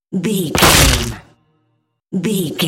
Dramatic hit bone
Sound Effects
heavy
intense
dark
aggressive
hits